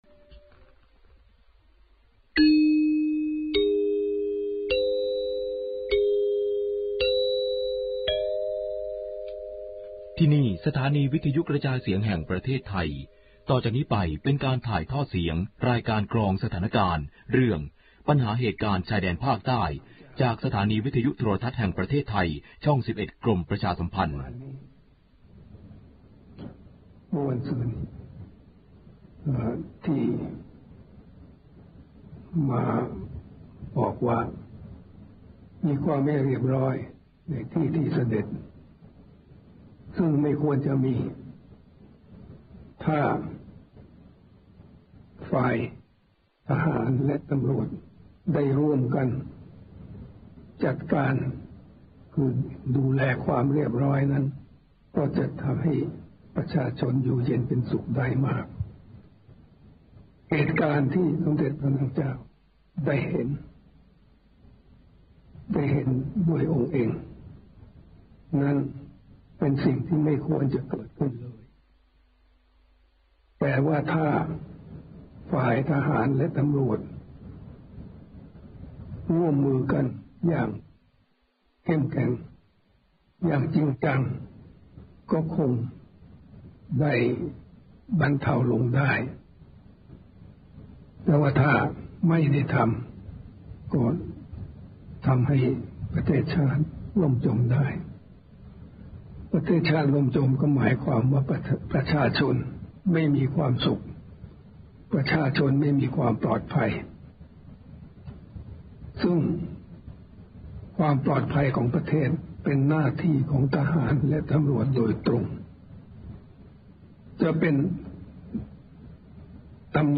พระราชดำรัสพระบาทสมเด็จพระเจ้าอยู่หัว ปัญหาเหตุการณ์ชายเเดนภาคใต้ 18 พฤศจิกายน 2547